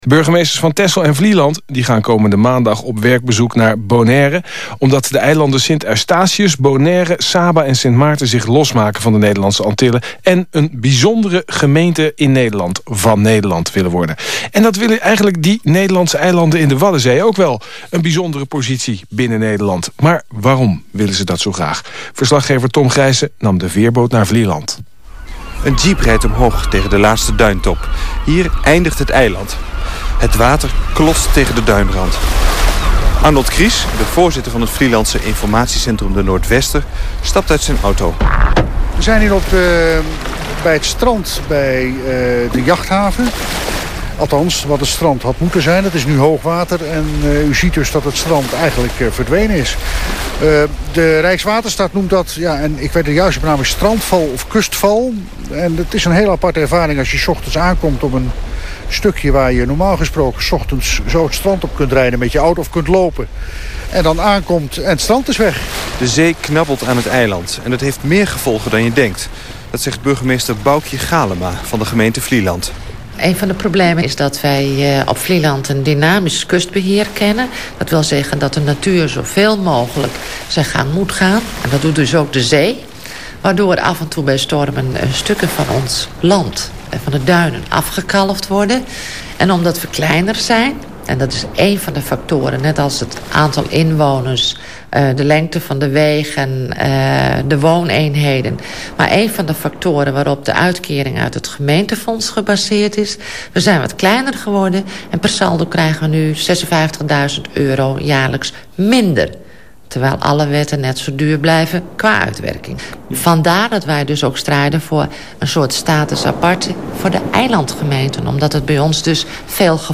deze reportage.